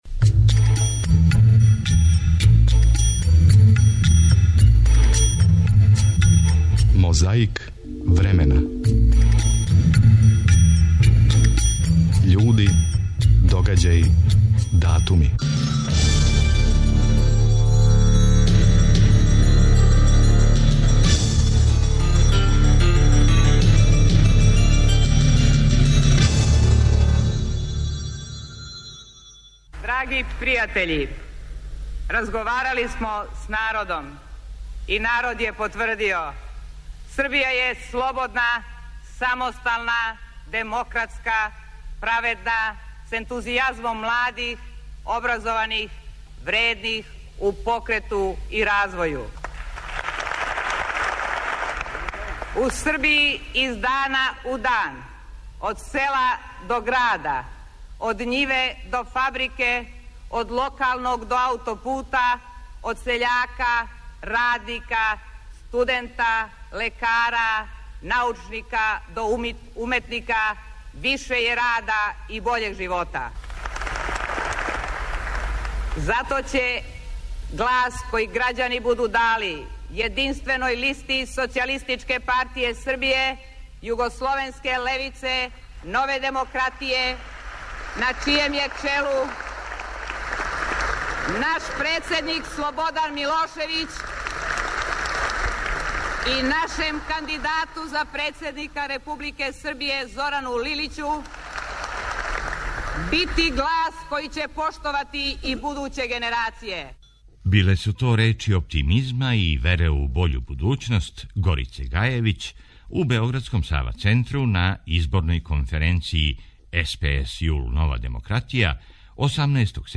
У време када се са стрепњом нагађало шта ће бити после, одржан је један од митинга српске опозиције. Било је то 13. септембра 1990. и на митингу су говорили многи па и Милан Комненић и Драгољуб Мићуновић.